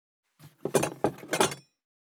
219,テーブル等に物を置く,食器,グラス,コップ,工具,小物,雑貨,コトン,トン,ゴト,ポン,
コップ効果音物を置く